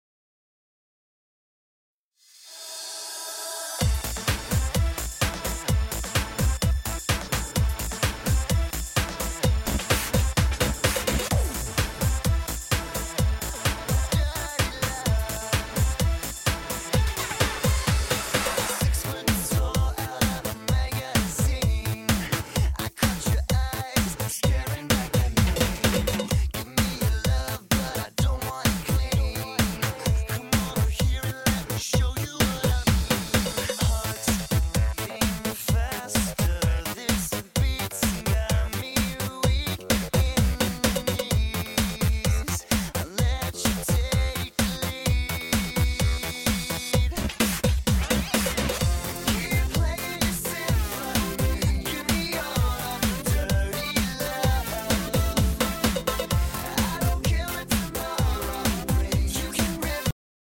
最新混音舞曲
種類：恰恰恰（chacha）